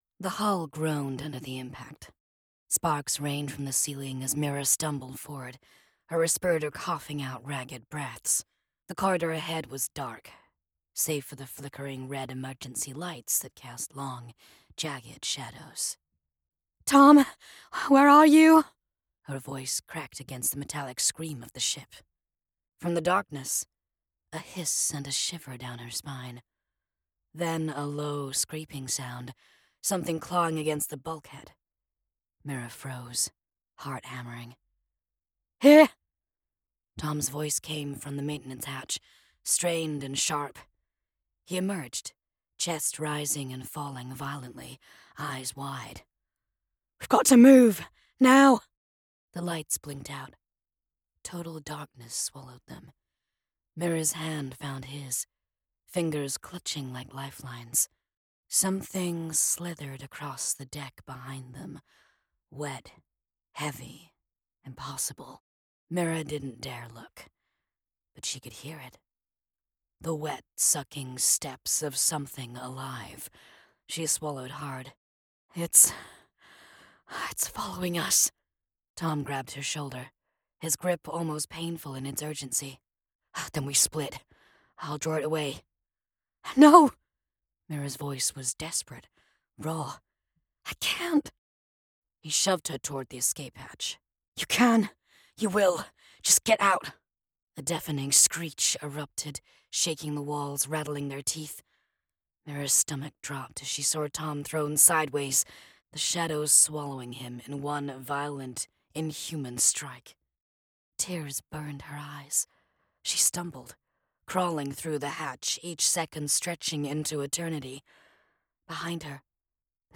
British Female Audiobook Narrator
Sci-Fi Download
3rd person, F/M, General American, Northern English. Mysterious, characterful.
scifi.mp3